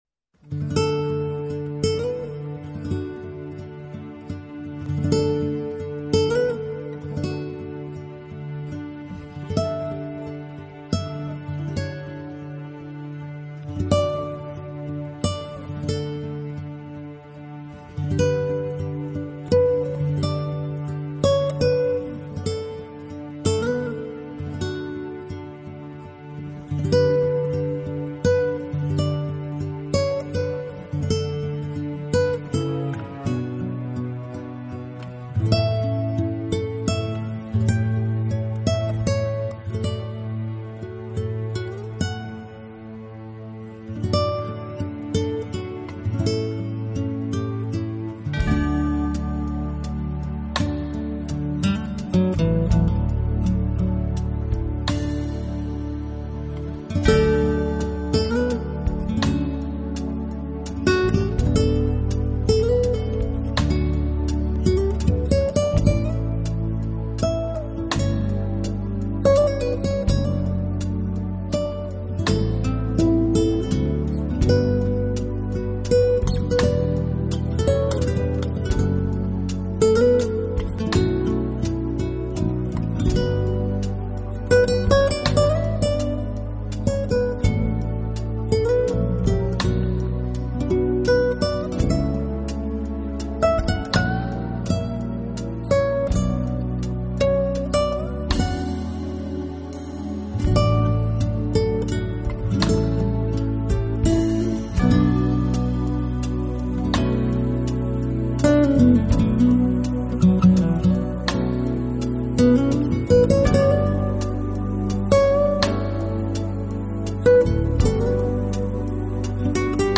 【爵士吉他】
音乐风格：Smooth Jazz